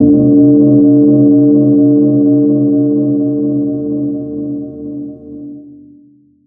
Efecto especial de campanada